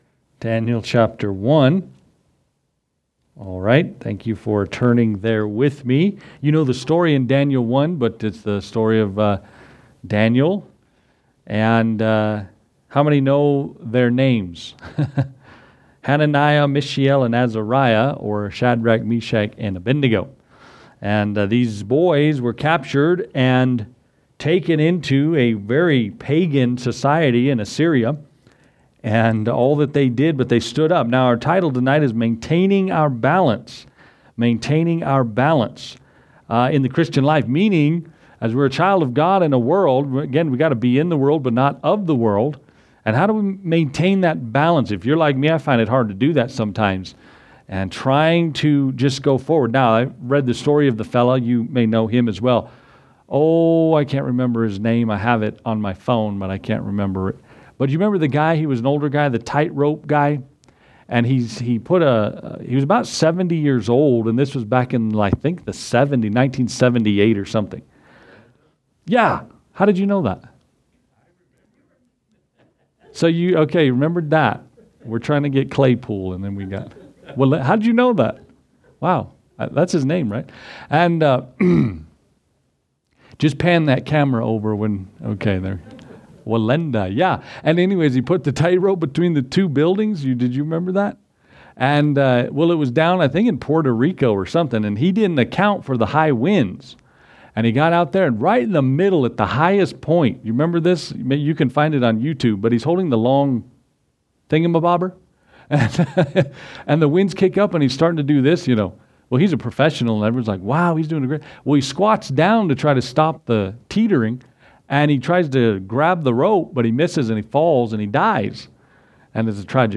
Gospel Message